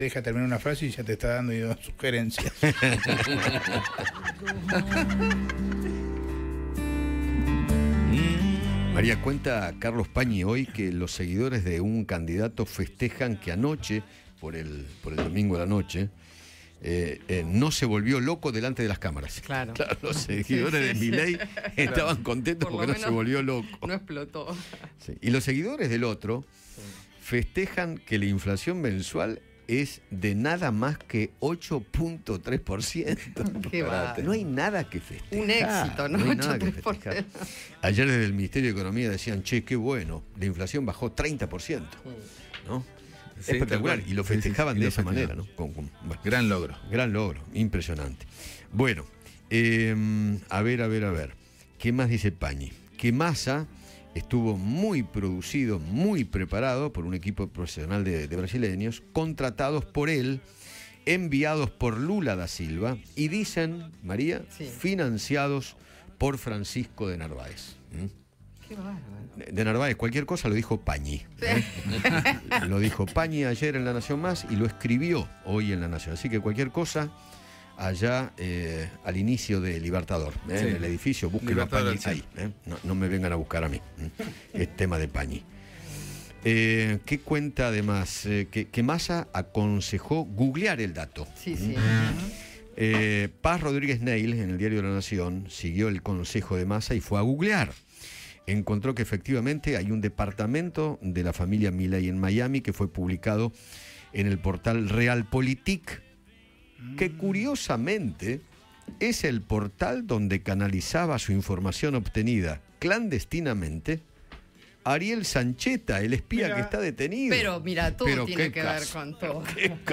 El conductor de Alguien Tiene que Decirlo dio detalles de quiénes serían los funcionarios en un eventual gobierno del candidato de Unión por la Patria.